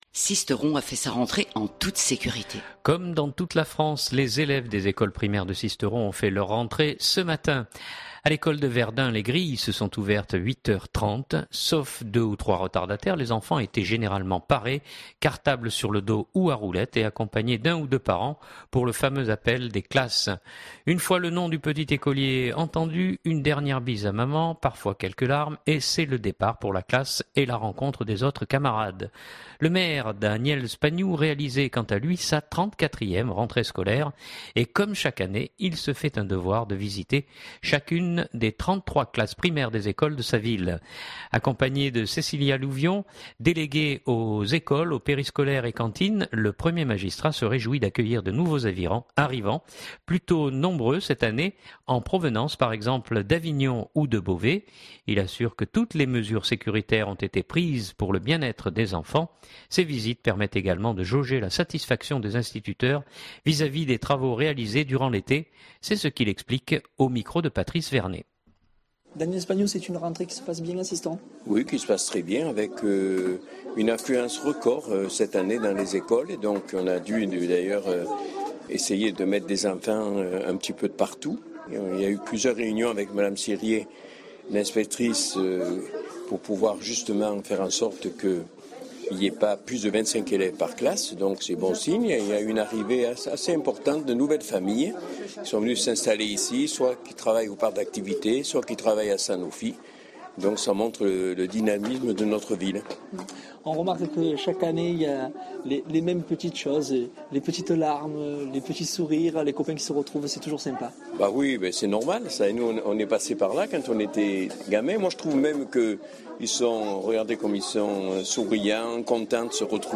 Le Maire Daniel Spagnou réalisait quant à lui sa 34ème rentrée scolaire, et comme chaque année il se fait un devoir de visiter chacune des 33 classes primaires des écoles de sa ville.